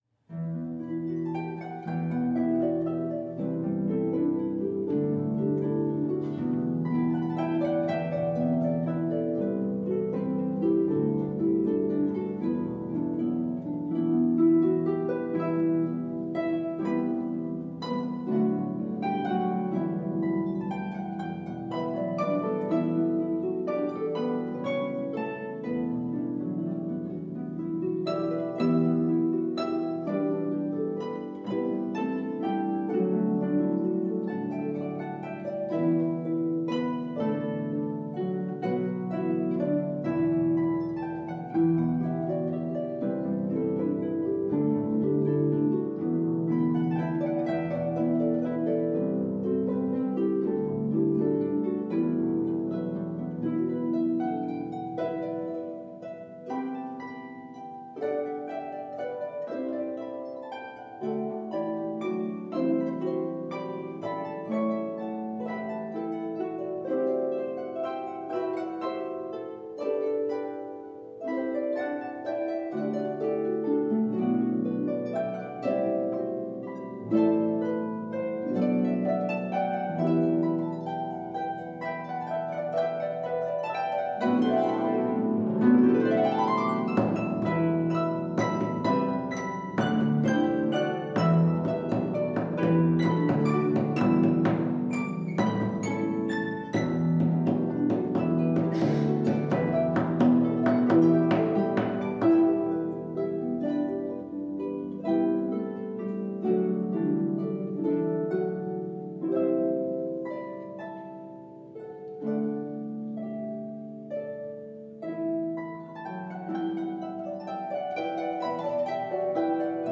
traditional English folk song